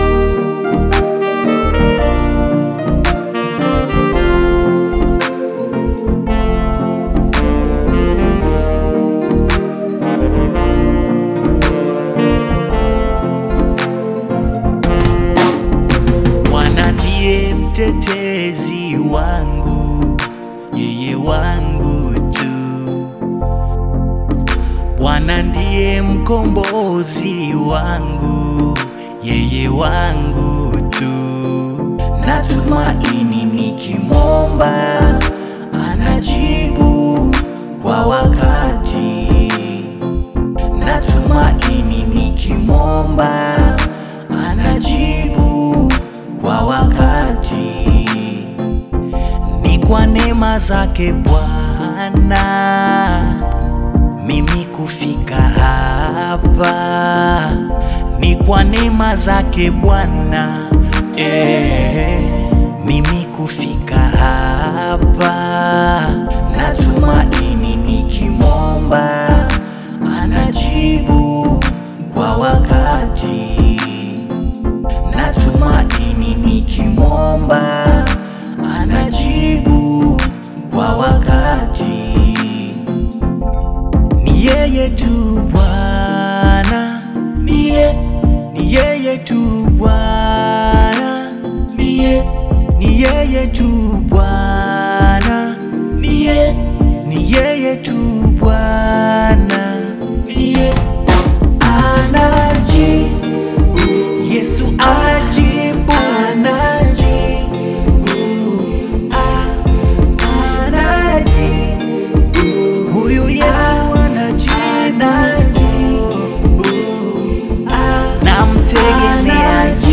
heartfelt Christian/Gospel single